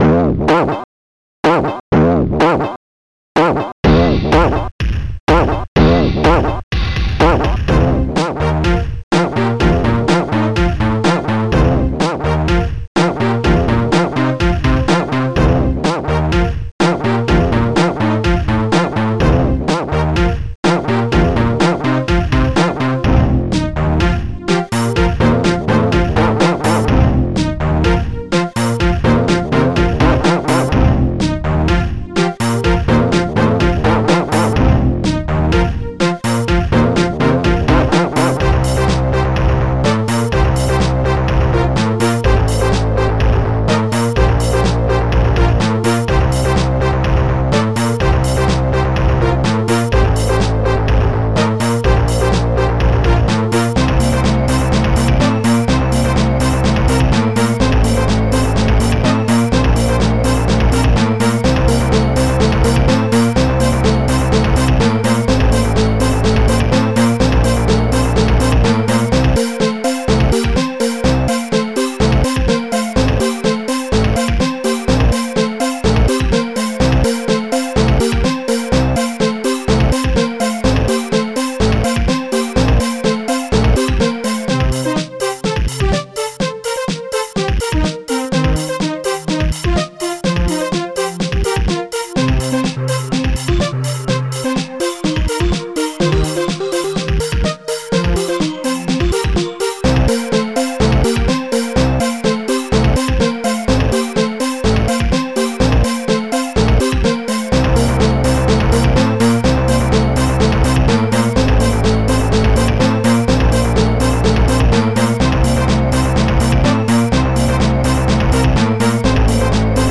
Type Amos Music Bank Tracker